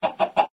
should be correct audio levels.